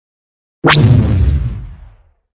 На этой странице собраны разнообразные звуки лазеров — от тонких высокочастотных писков до мощных энергетических залпов.
Космический световой бластер